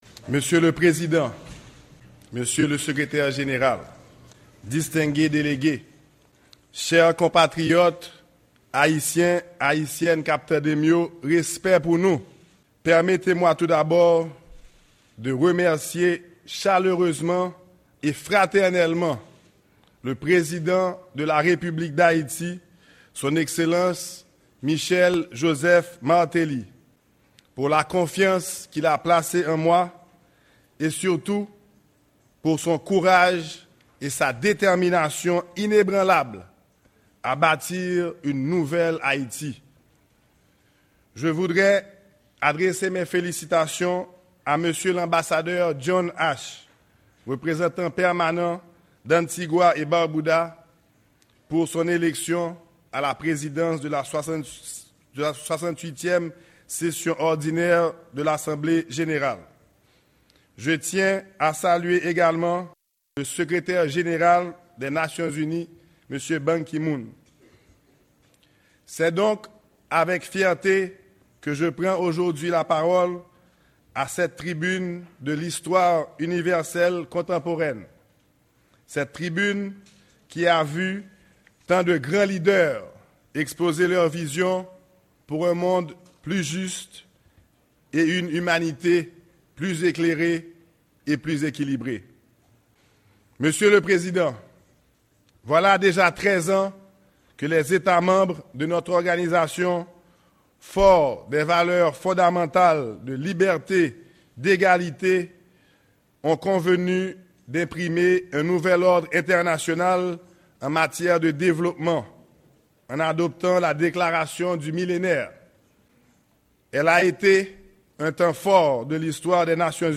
Mesaj Premye Minis Laurent Lamothe devan Asanble Jeneral l'ONU